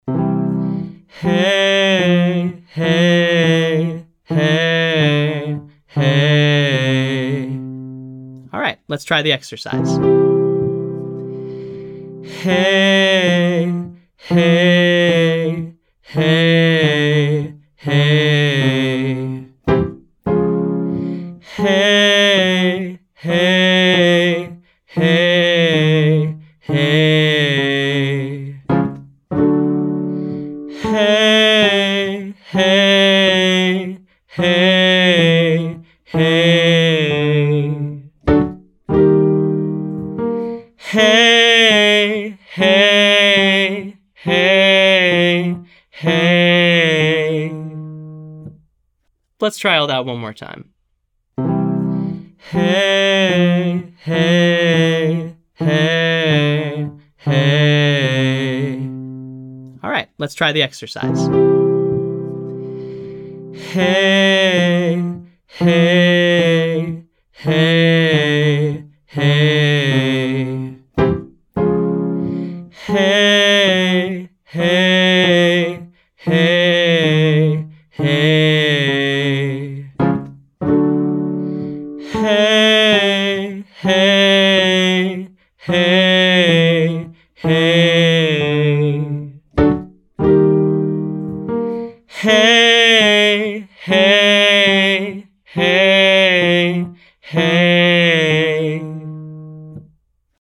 Exercise: Quick “falls”: 54, 43, 32, 21
Practice this on a descending 5-tone scale.